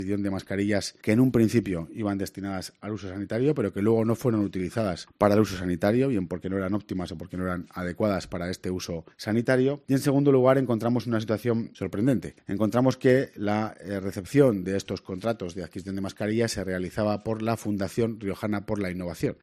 El portavoz del Ejecutivo riojano, Alfonso Domínguez, explica que el actual Ejecutivo ha tenido cono